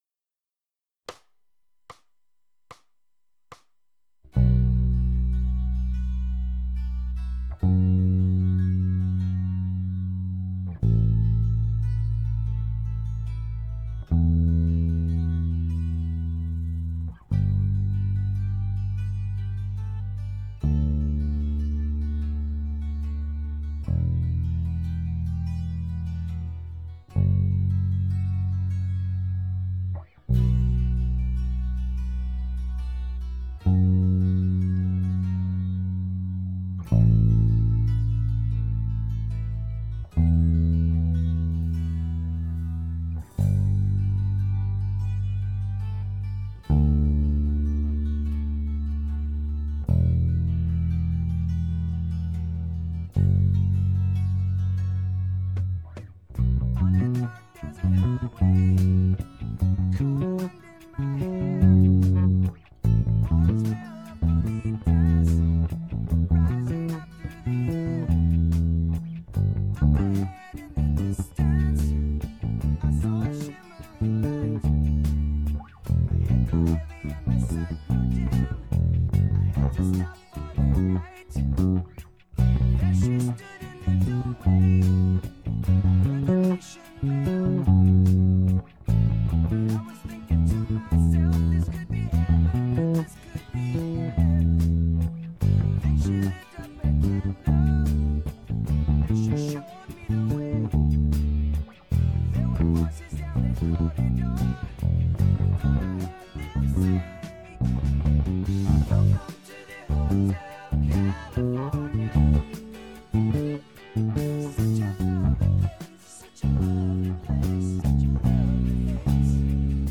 Nur bass